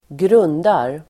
Uttal: [²gr'un:dar]